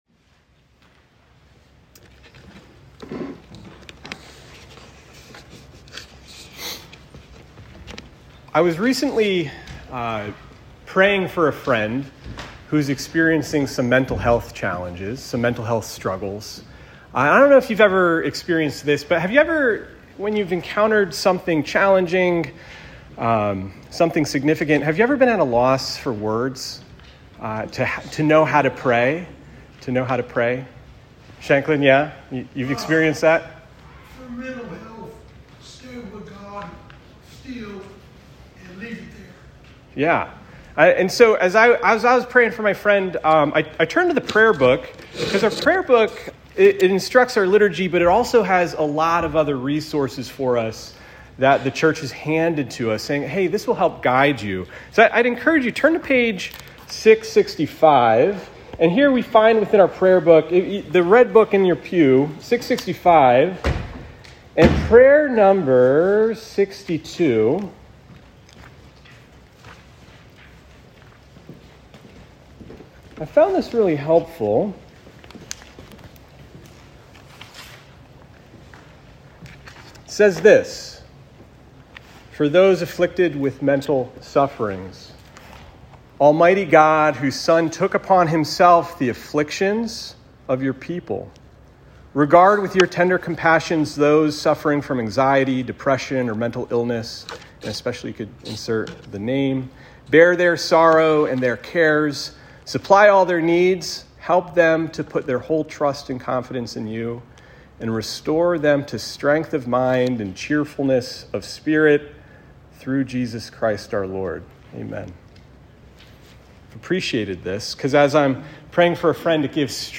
Sermons | Anglican Church of the Ascension